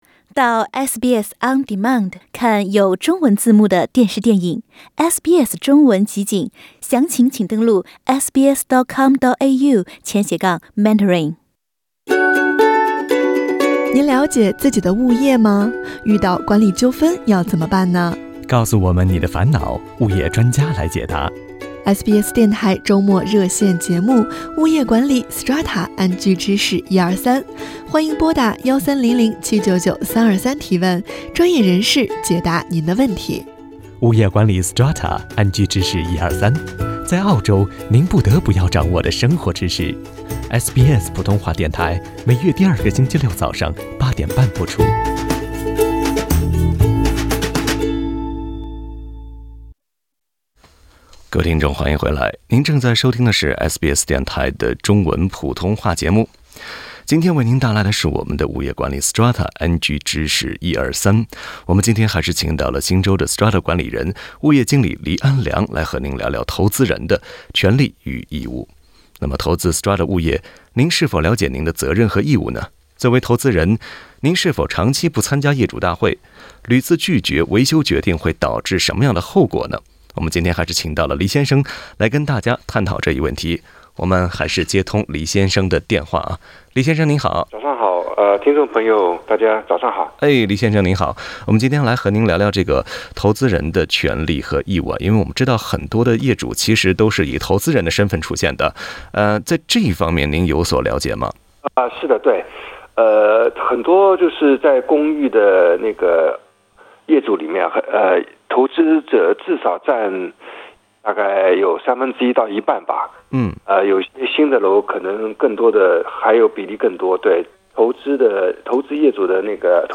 与此同时，听众朋友们还提出了一些其他问题，比如如何申请将阳台改造成阳光房？